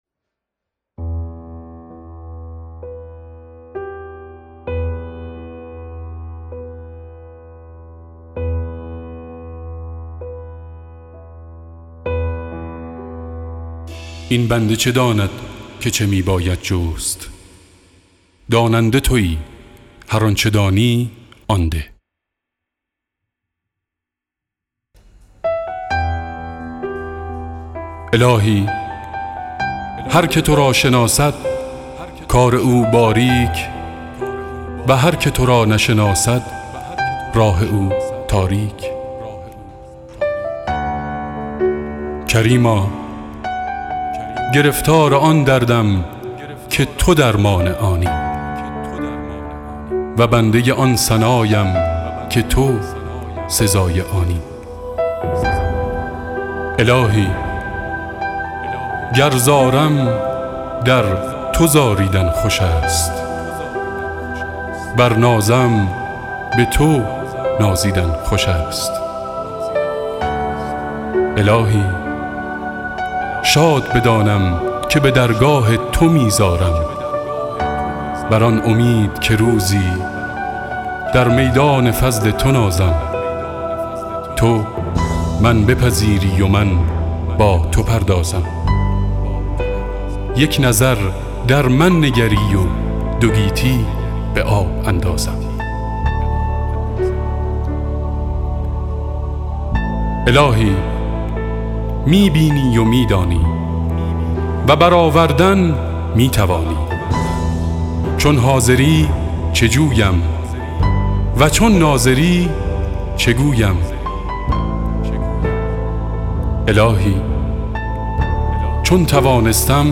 دکلمه الهی نامه و گرگ و انسان
بسيار عالى بود،متن دكلمه فوق العاده بود صداى گرم و دلنشين شما هم زيباتر كرده بود،واقعا لذت بردم ،با آرزوهاى موفقيت روز افرون براى شما🌹👏